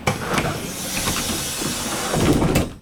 Bus Door 1 Sound
transport
Bus Door 1